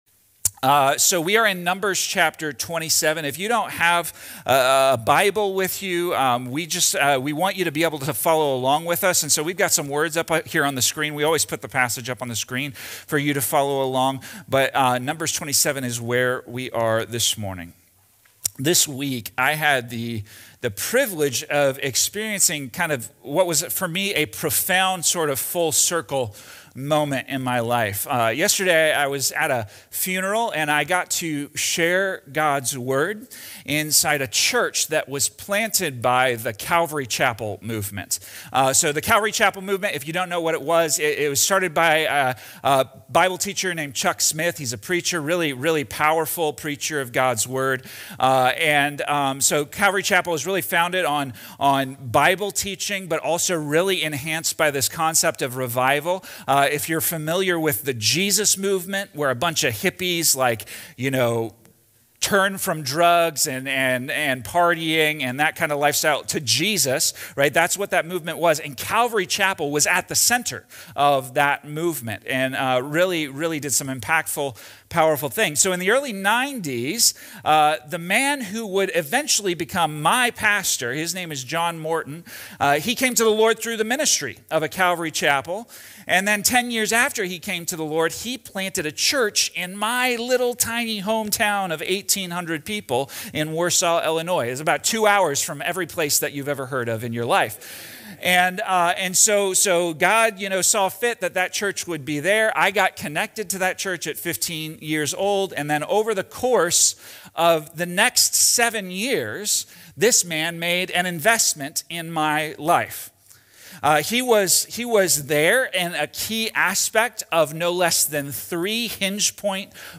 This message from the Pursuing God’s Call series focuses on what it means to be a faithful disciple and discipler through the story of Moses and Joshua in Numbers 27. Moses’ example shows how spiritual growth happens through intentional relationships—teaching, correcting, and helping others walk closely with God.